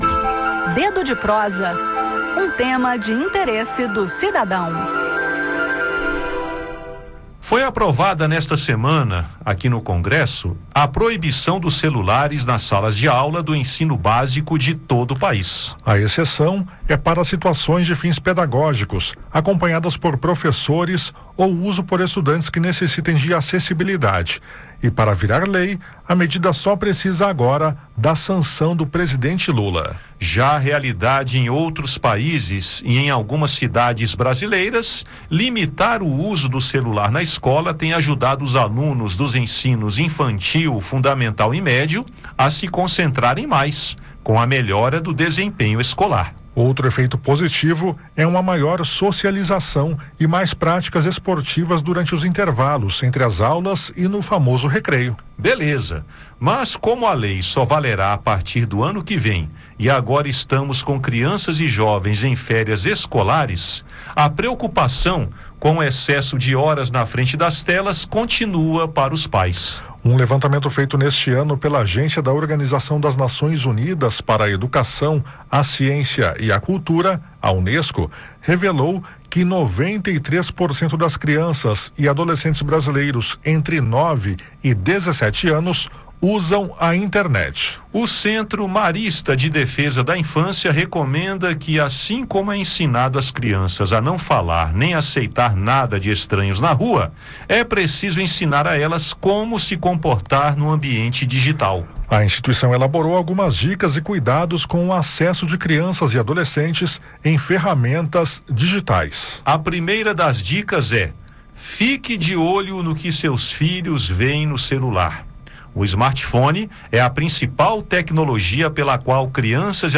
No bate-papo